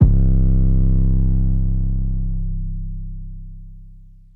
Hoodrich 808.wav